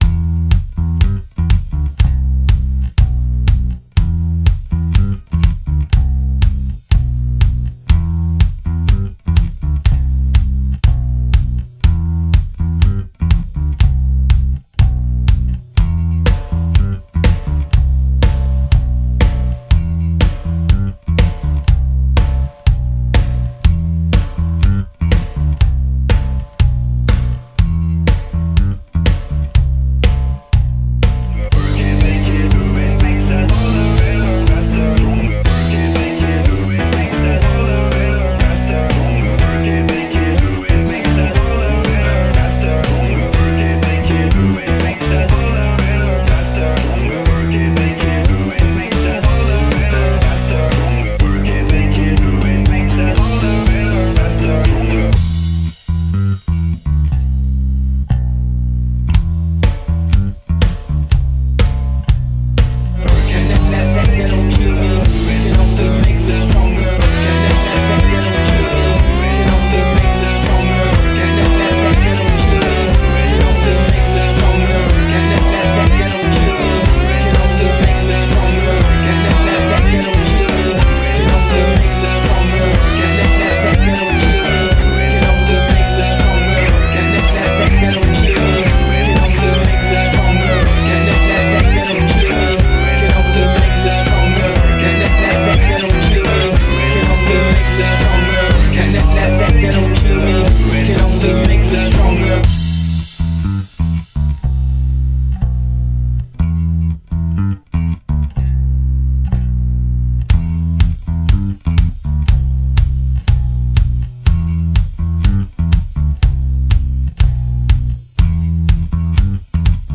Genres: Hip Hop, Rock, Top 40